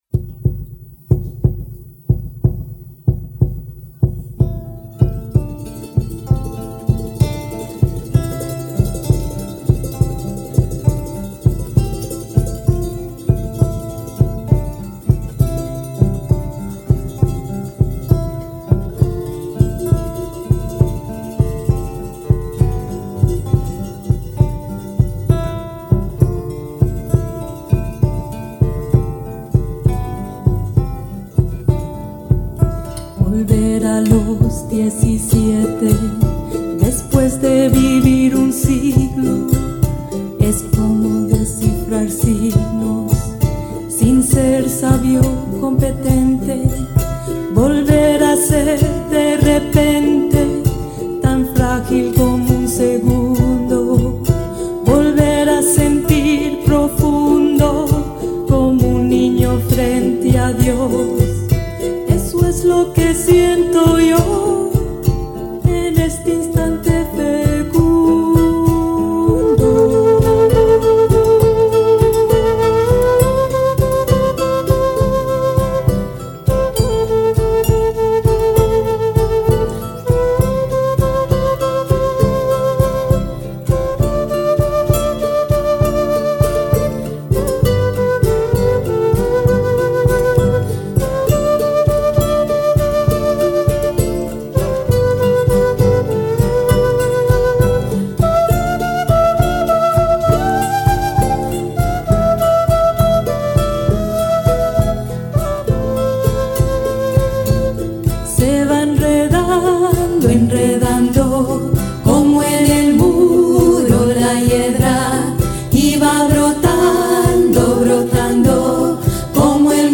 Genre:Folk, World, & Country